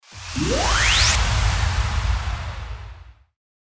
FX-1475-WIPE
FX-1475-WIPE.mp3